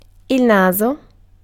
Ääntäminen
France: IPA: [ne]